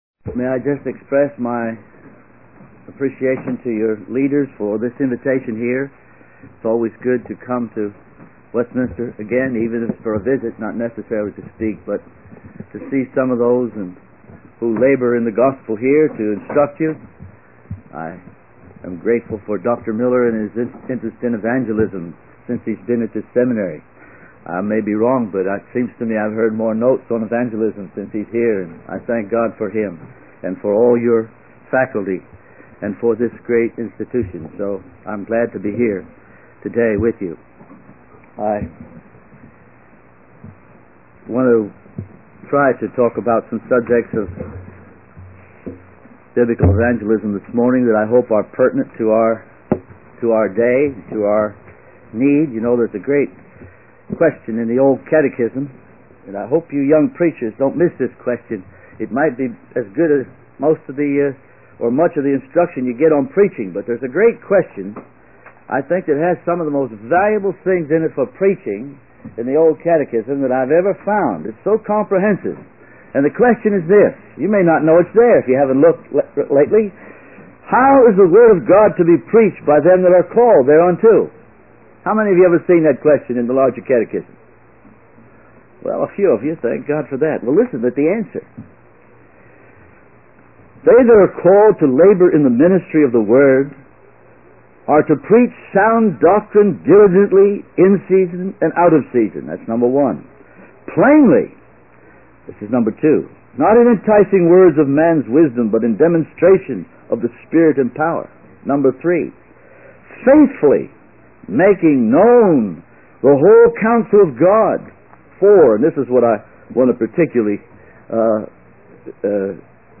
In this sermon, the preacher discusses the importance of addressing the needs of the audience and applying the teachings of the Bible to their lives. He emphasizes the necessity of evangelism and the biblical doctrine of assurance.